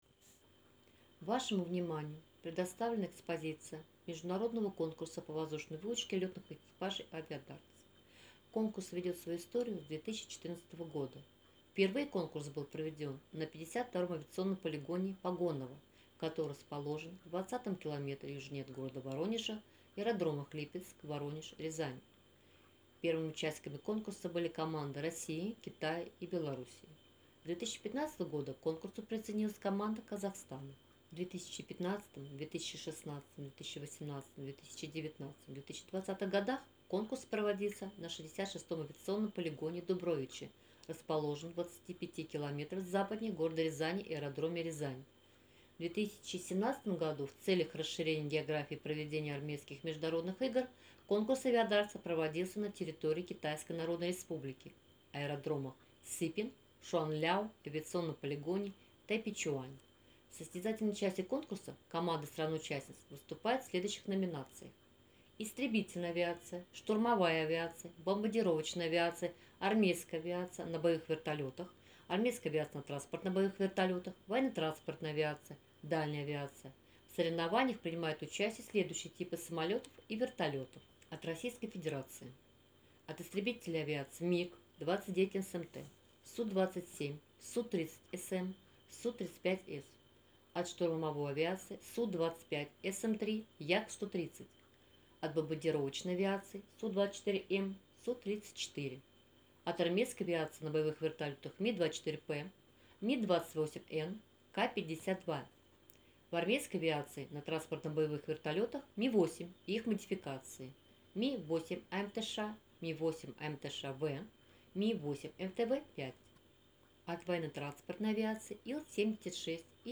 Аудио гид:
audiogid-Aviadarts.mp3